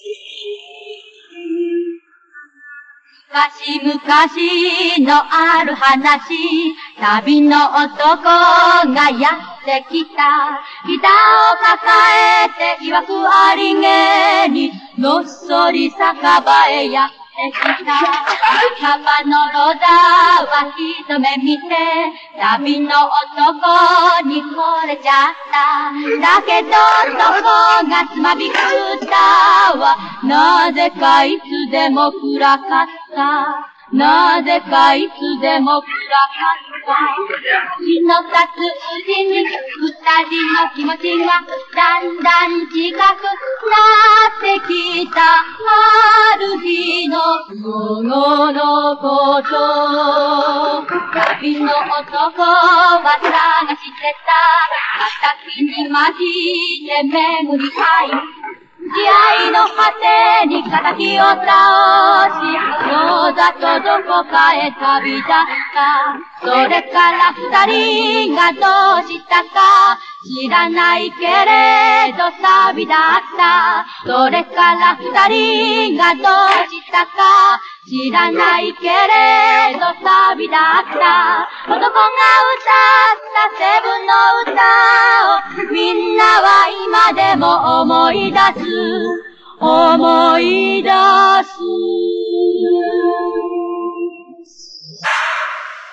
ковбойская баллада?
ФФЕААААААААААААААААААААСТЬ ПЕСНЯ  ХОТЫ ИСПОЛНЫЯЮТ  ЯПОНЕРКИ  НО К В СТИЛЕ ВЕСТЕРН